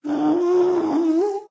moan5.ogg